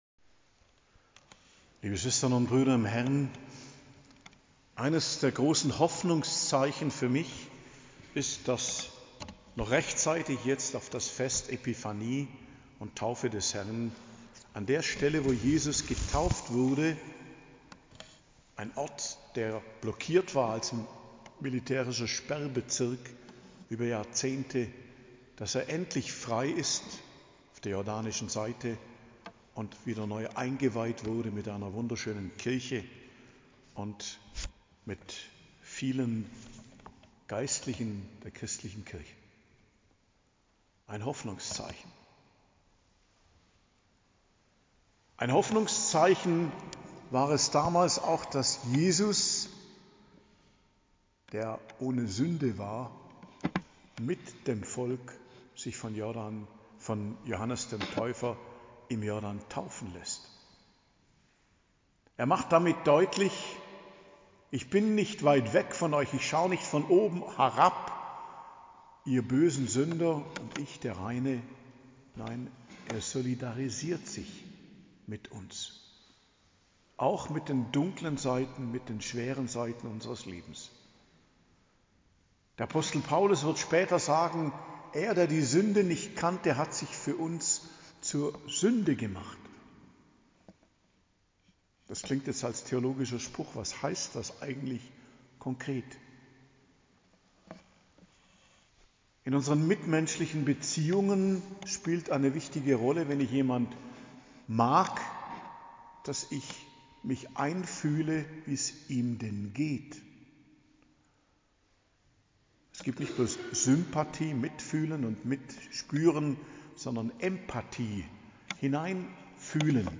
Predigt zum Fest Taufe des Herrn, 12.01.2024